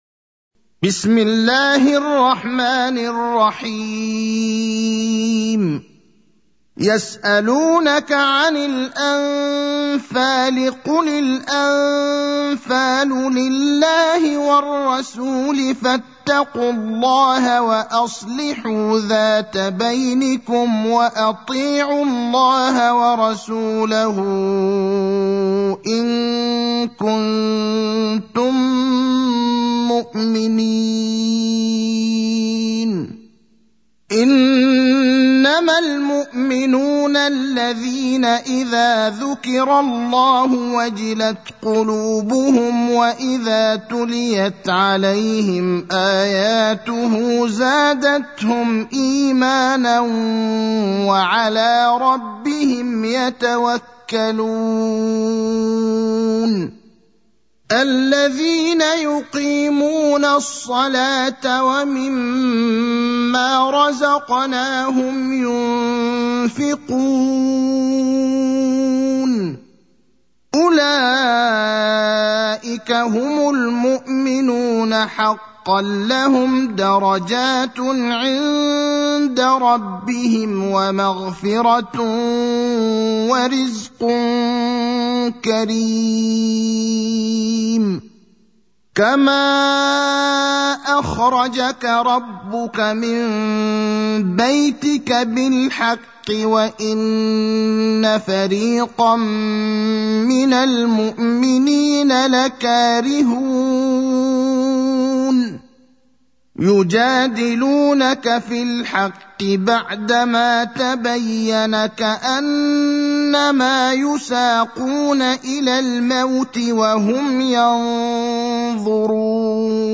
المكان: المسجد النبوي الشيخ: فضيلة الشيخ إبراهيم الأخضر فضيلة الشيخ إبراهيم الأخضر الأنفال (8)